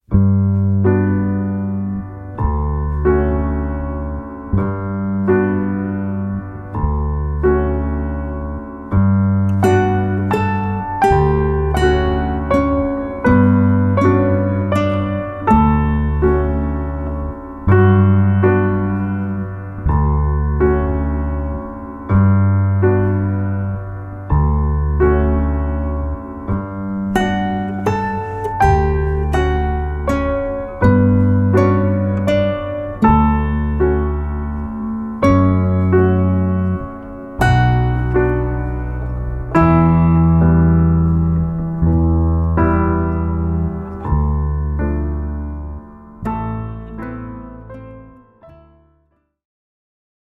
Acoustic Guitar and Piano performance